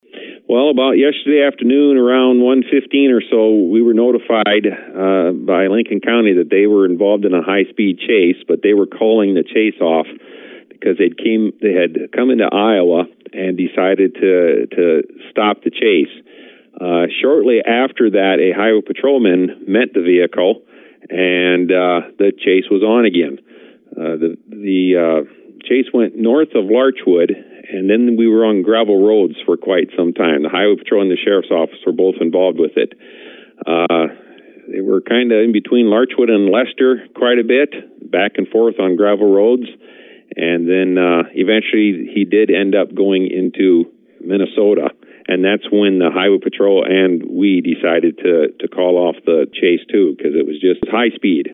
Lyon County Sheriff Stewart Vander Stoep tells us what happened in his county.